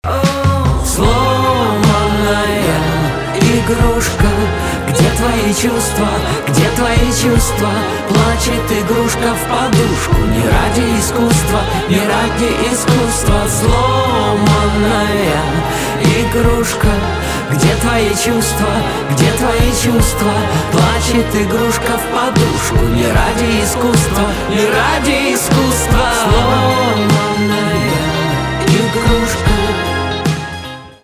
инди
грустные , печальные , гитара , барабаны , чувственные